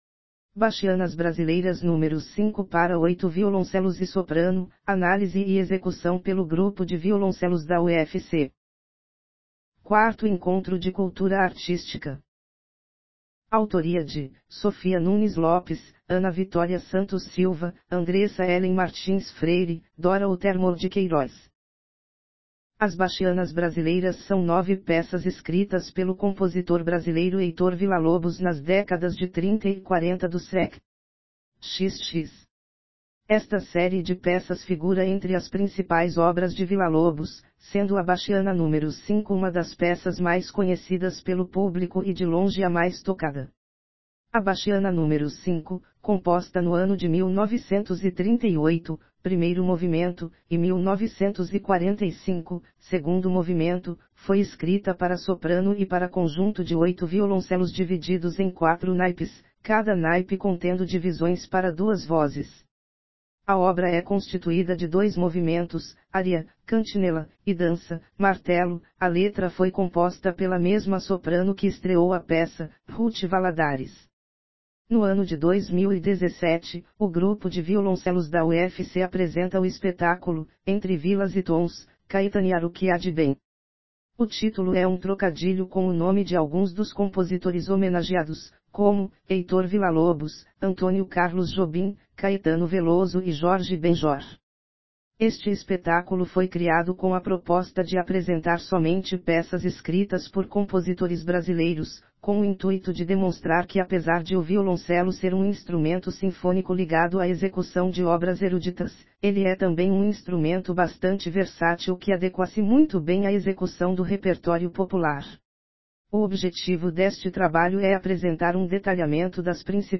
BACHIANAS BRASILEIRAS NÚMERO 5 PARA 8 VIOLONCELOS E SOPRANO: ANÁLISE E EXECUÇÃO PELO GRUPO DE VIOLONCELOS DA UFC | Encontros Universitários da UFC